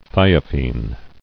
[thi·o·phene]